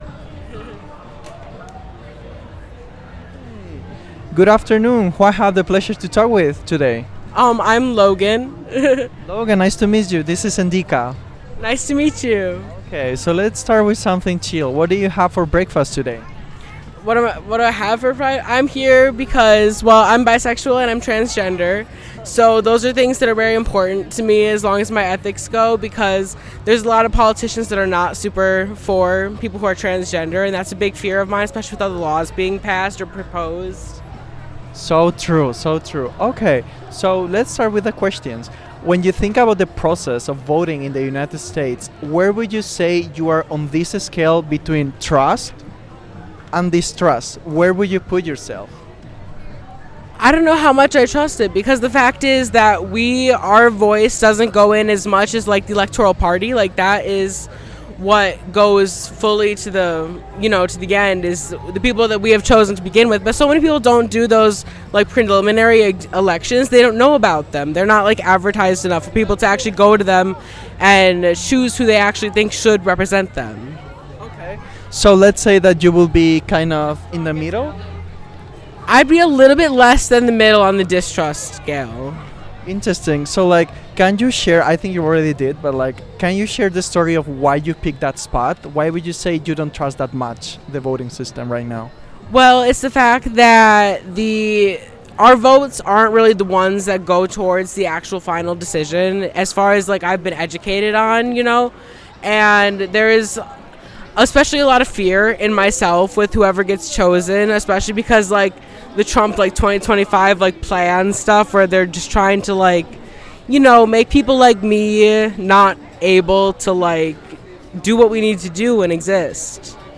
Location MKE Pridefest